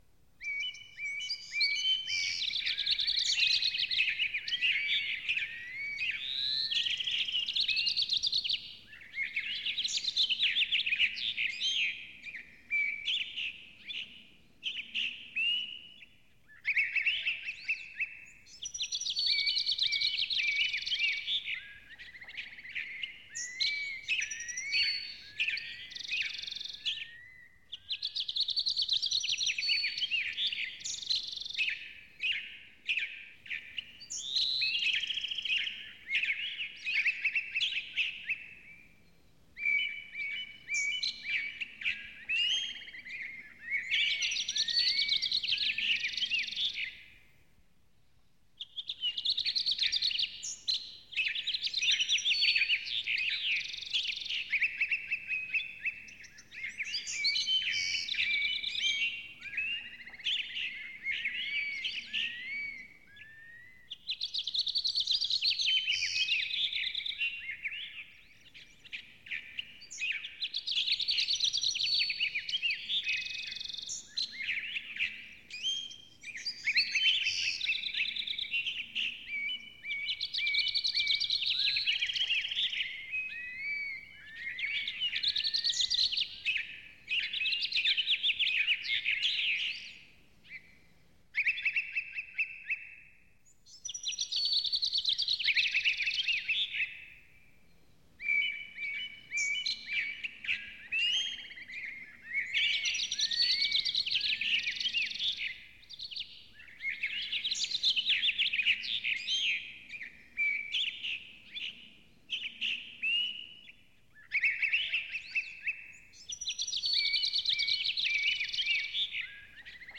the-sounds-of-birds-singing